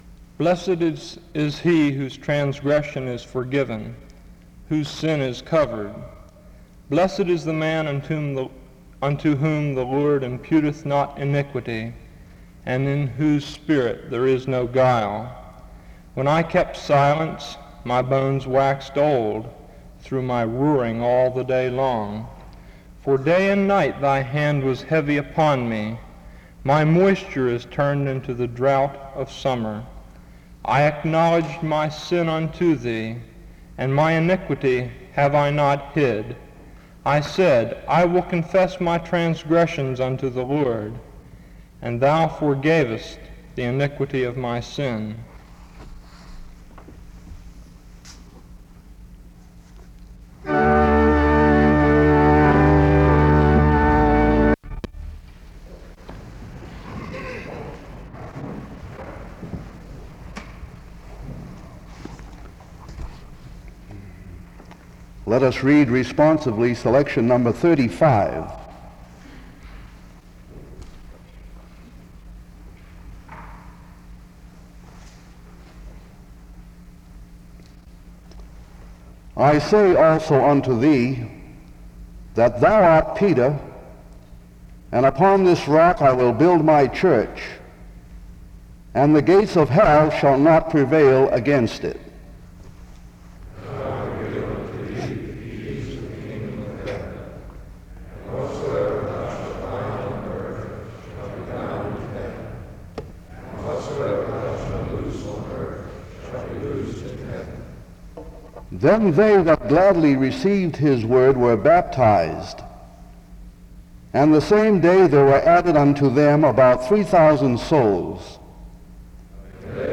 The service starts with an opening scripture reading from 0:00-0:46. A responsive reading takes place from 1:05-3:15. A prayer is offered from 3:22-5:02.
The service closes in prayer from 14:35-16:22.
SEBTS Chapel and Special Event Recordings SEBTS Chapel and Special Event Recordings